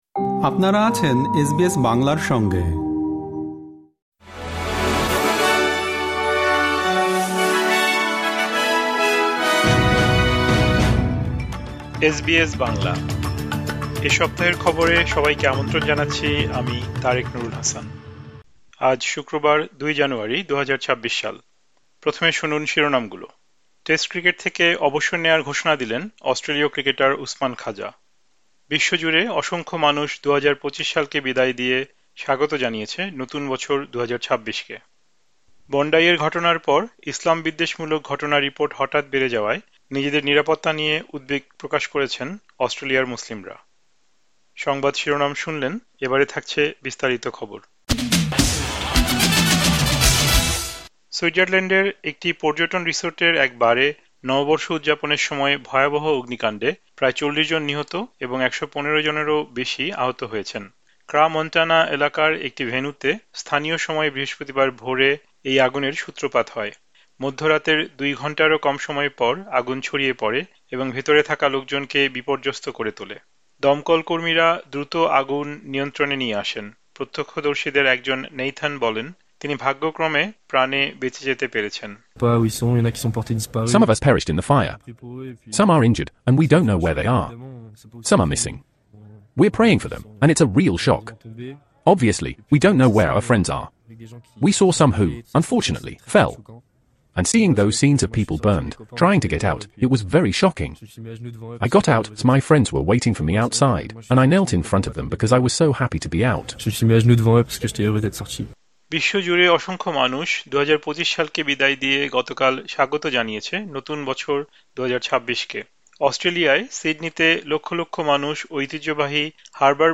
আজ শুক্রবার, ২ জানুয়ারিতে প্রচারিত অস্ট্রেলিয়ার এ সপ্তাহের জাতীয় ও আন্তর্জাতিক গুরুত্বপূর্ণ সংবাদ শুনতে উপরের অডিও-প্লেয়ারটিতে ক্লিক করুন।